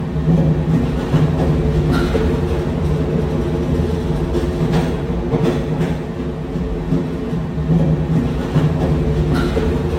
Bruitage – Dans le train – Le Studio JeeeP Prod
Bruitage haute qualité créé au Studio.
Dans-le-train.mp3